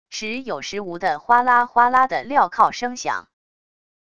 时有时无的哗啦哗啦的镣铐声响wav音频